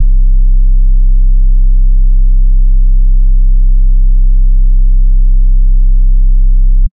CleanSub_YC.wav